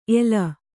♪ ela